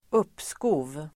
Uttal: [²'up:sko:v]